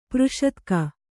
♪ přṣatka